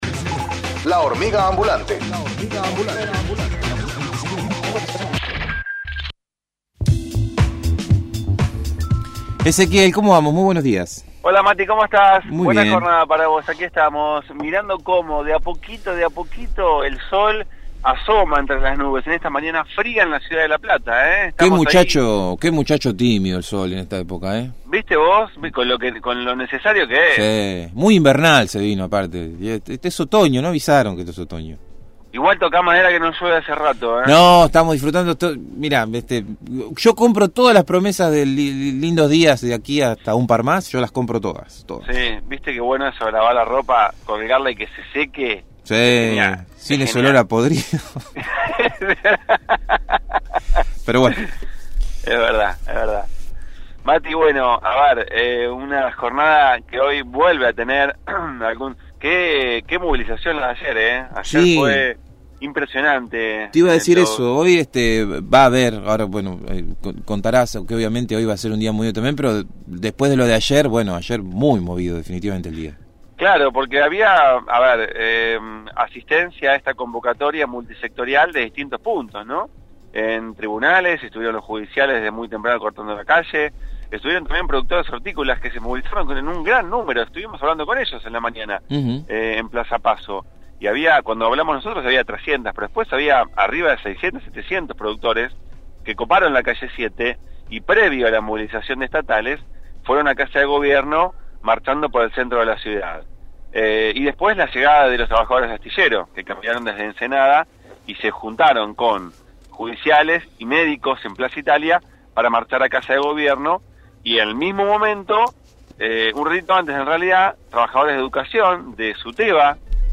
MÓVIL/ Repaso de marchas previstas para el día de hoy